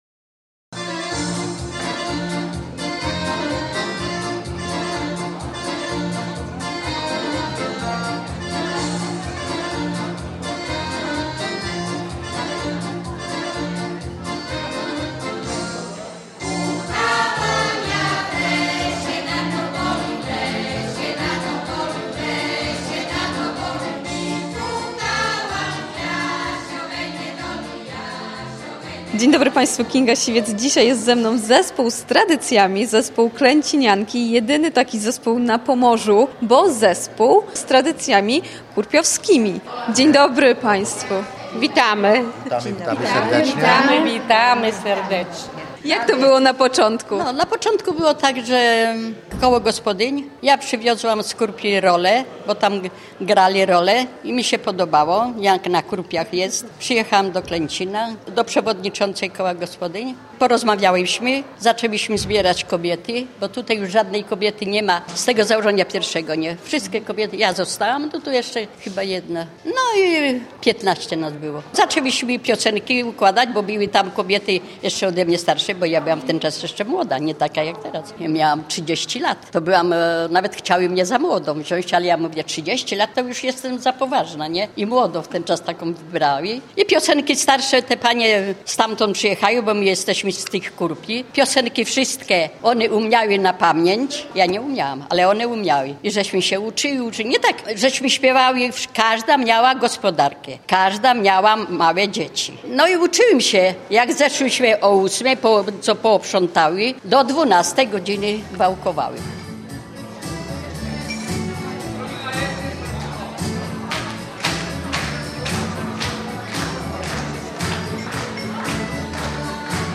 To jedyny taki zespół na Pomorzu. Z niemal 50-letnią tradycją, śpiewający piosenki kurpiowskie.
W zespole śpiewają równocześnie seniorki, założycielki zespołu, ich córki oraz wnuczki.